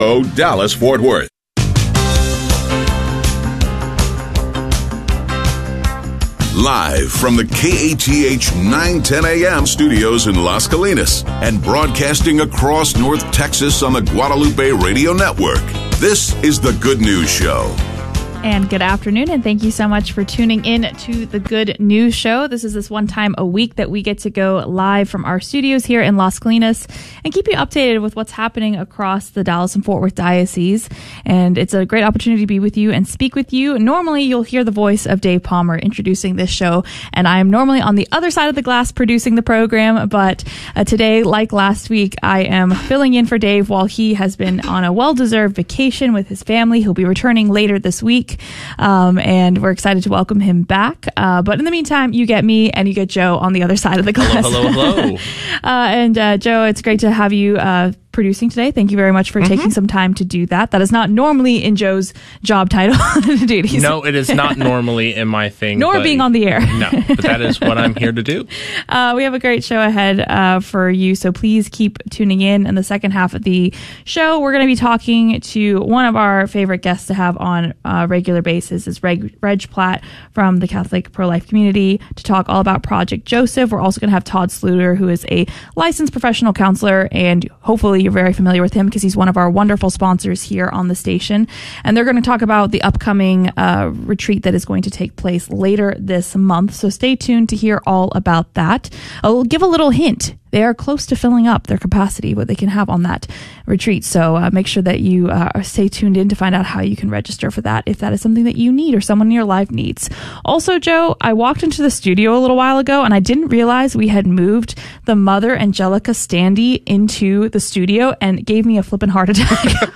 One hour of solid, Catholic conversation for your Monday afternoon. Focuses on issues pertinent to North Texas Catholics.